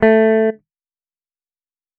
De eerste eigentrilling, in het bovenstaande voorbeeld de A van 440Hz, is daarbij het makkelijkst aangeslagen.
Daarom noemen we de resulterende toon alsnog een A. Er zullen echter ook altijd andere eigentrillingen te horen zijn, en de verschillende verhouding waarin die tonen voorkomen zorgt ervoor dat we het verschil kunnen horen tussen een A van de piano en die van de gitaar:
Gitaar:
qu-gitaar.wav